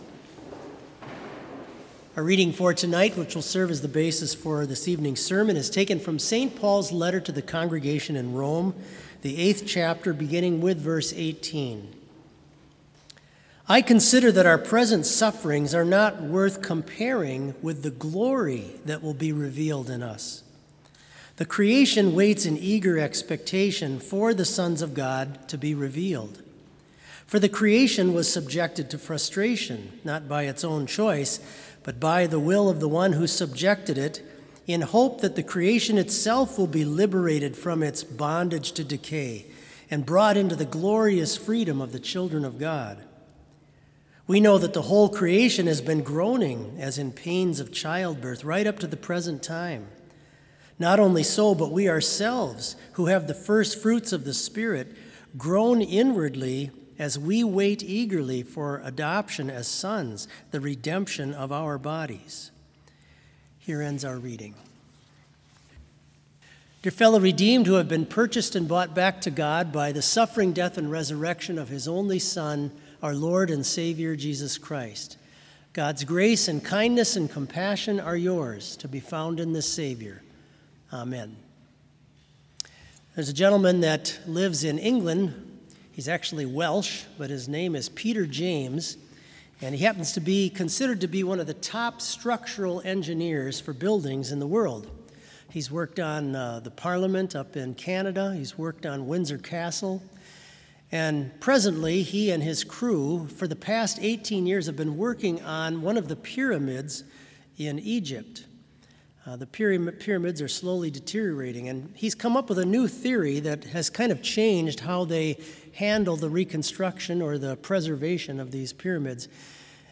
Vespers worship service in BLC's Trinity Chapel
Complete service audio for Vespers - September 16, 2020